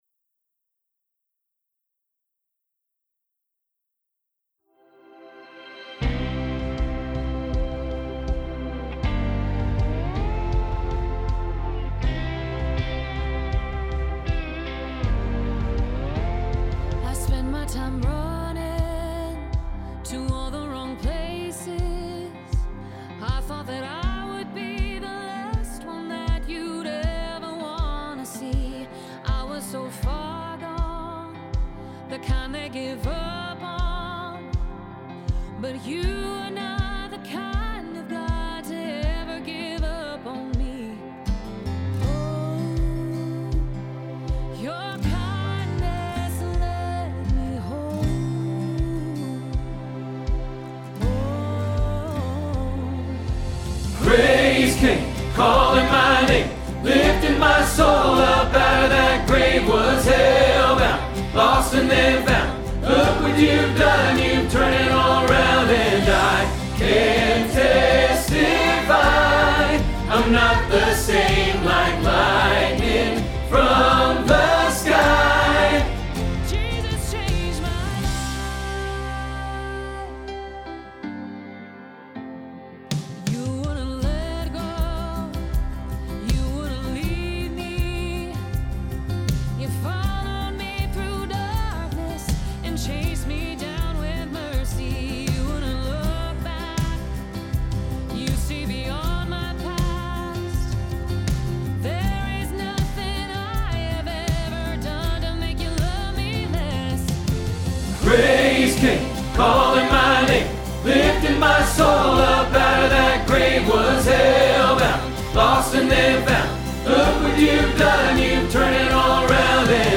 Jesus Changed My Life – Tenor – Hilltop Choir
01-Jesus-Changed-My-Life-Tenor-PTX.mp3